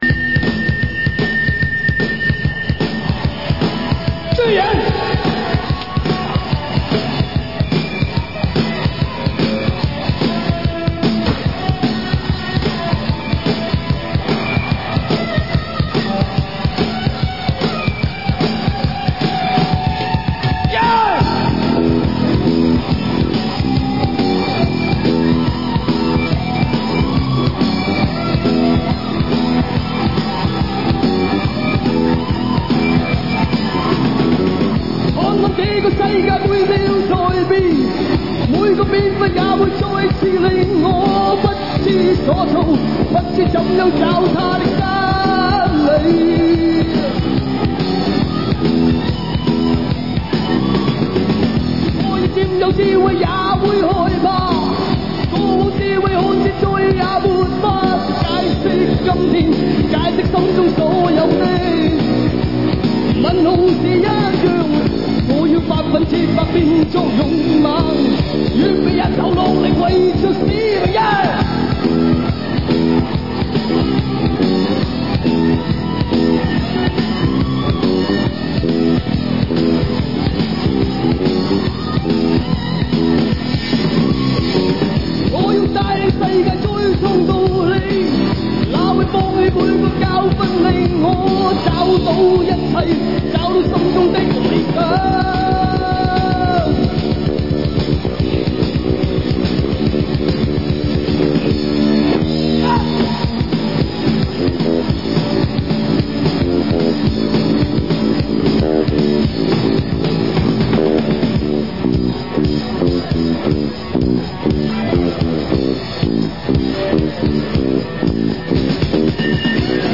1986年【台北演唱会】